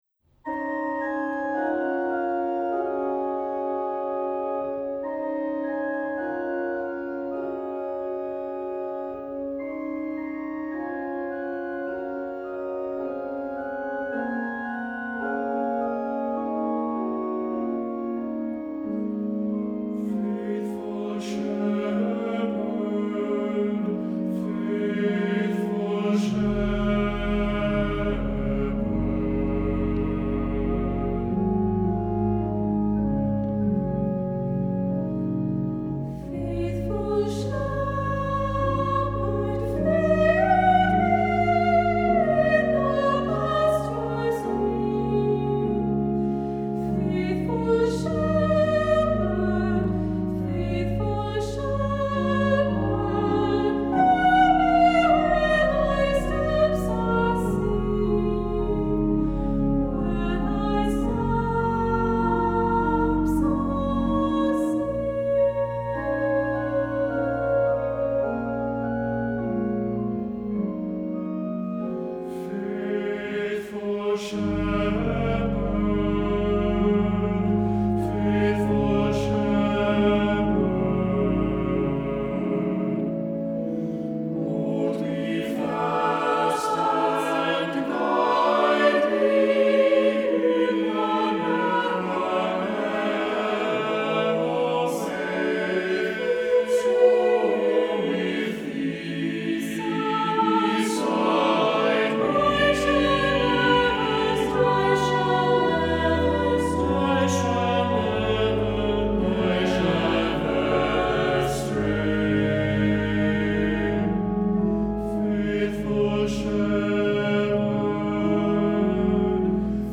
Accompaniment:      With Organ
Music Category:      Christian